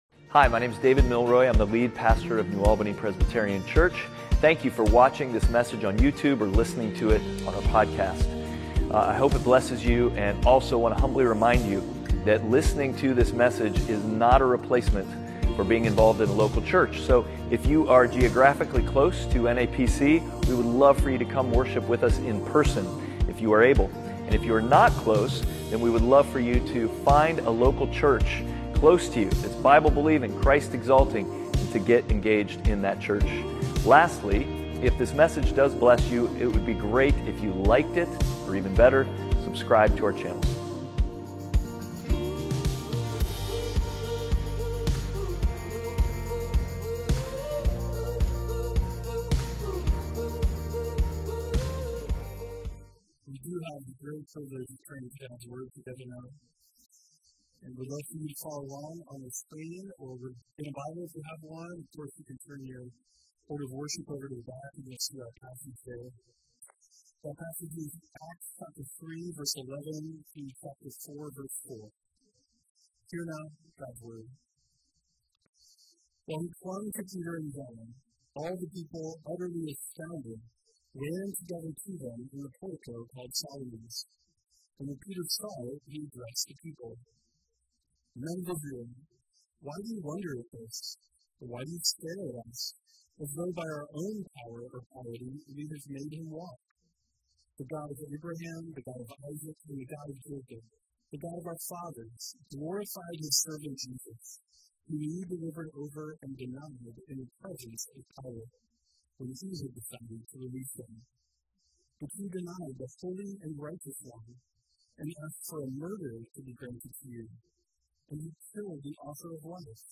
Outward Passage: Acts 3:11-4:4 Service Type: Sunday Worship « Outward